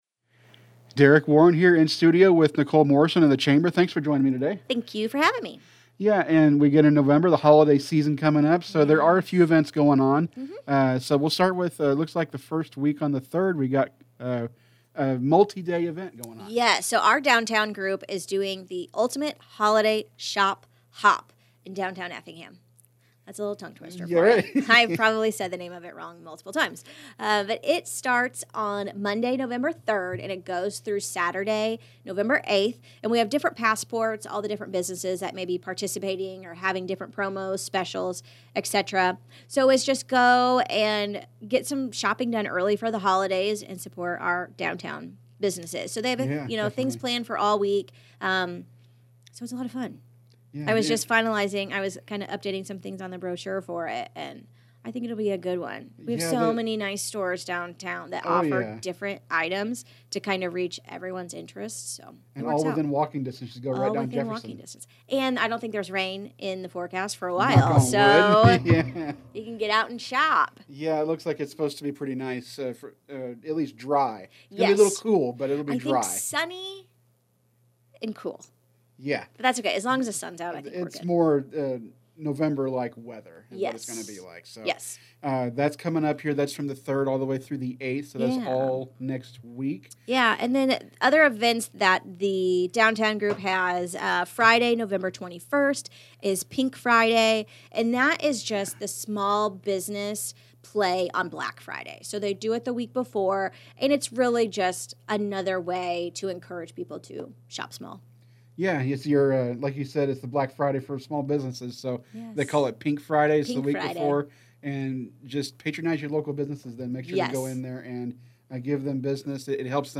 A Look At November Chamber Events In Interview